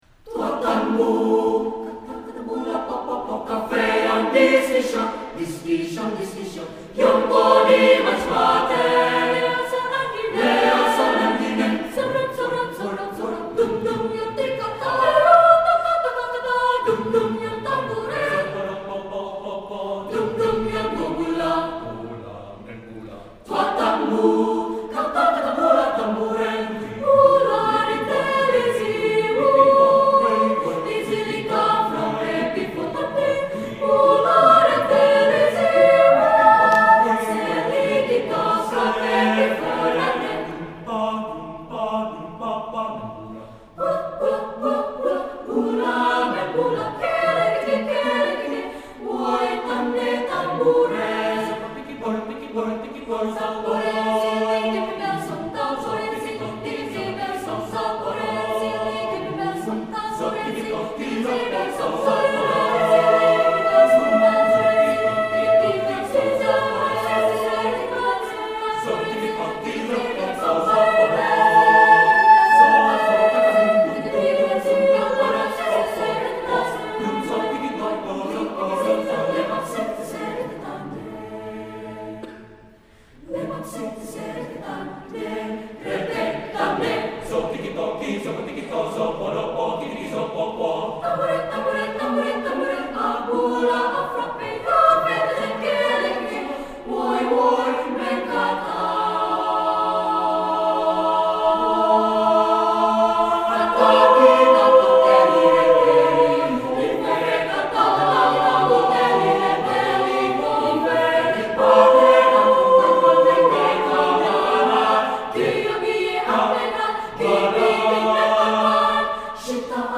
Voicing: "TTBB"